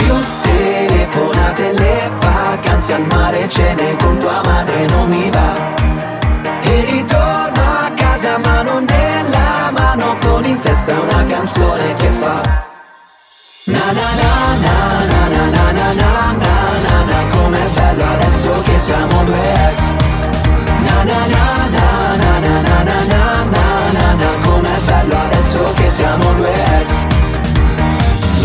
Genere: ,pop,trap,dance,rap,remix,italiana,hit